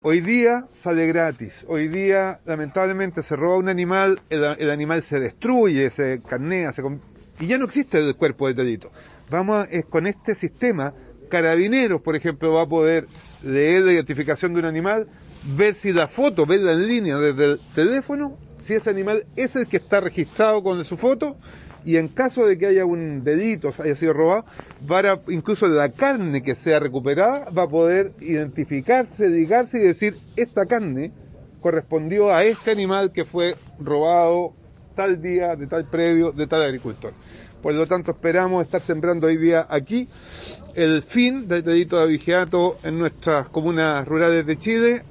La ceremonia de lanzamiento del “Banco ADN Animal” contó con la presencia del Subsecretario de Agricultura José Ignacio Pinochet, quien señaló que “esperamos que Nacimiento y las otras cinco comunas que forman parte de este proyecto, sean las primeras del país donde desaparezca el delito de abigeato».